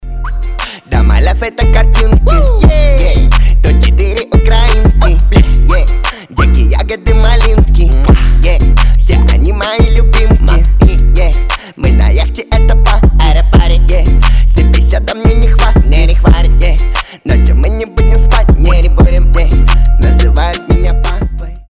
свист
ритмичные
Хип-хоп
русский рэп
веселые